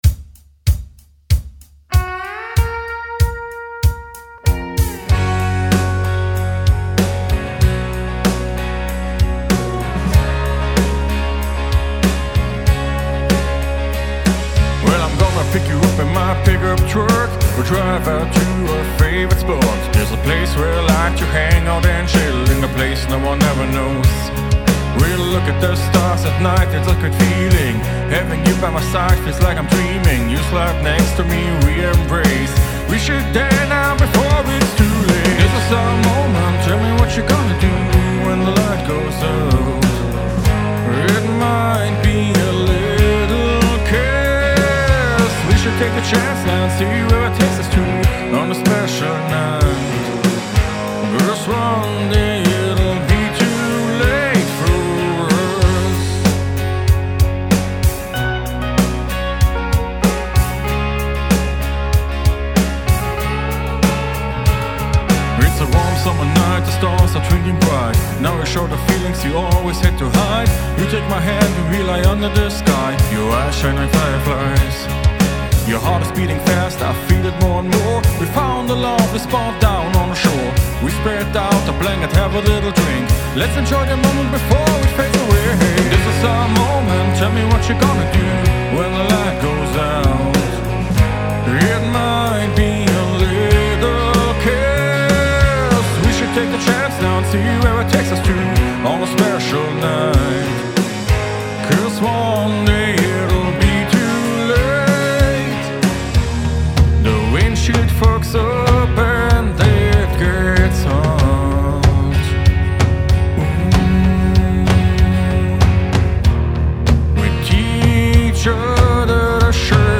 When the lights go out - Acoustic Pop Rock - Hilfe zum mix.
Anbei mal mein mix: Die WAVE Spuren gibt es hier: (95bpm) Google Drive ZIP File